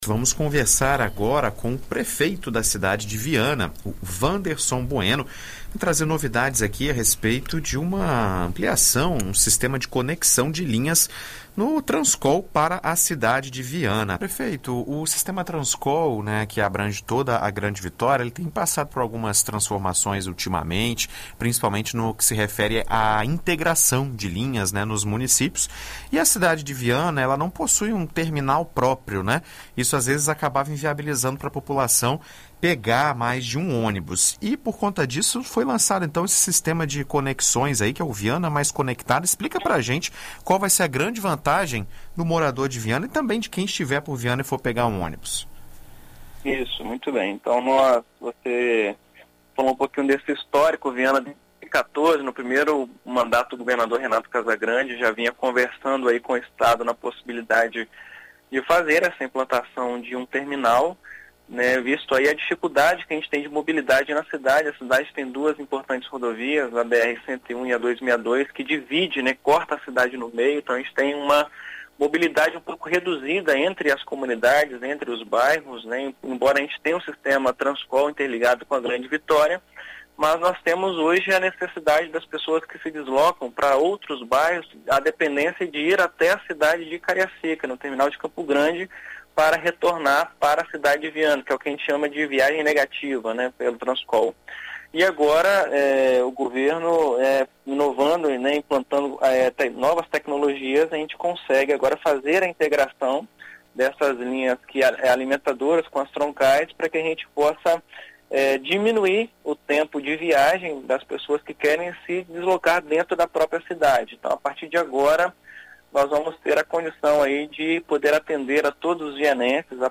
Na BandNews FM, o prefeito de Viana, Wanderson Bueno explica os detalhes da nova implantação no município
Em entrevista à BandNews FM Espírito Santo nesta sexta-feira (27), o prefeito de Viana, Wanderson Bueno, traz mais detalhes sobre a implantação do sistema de mobilidade no município.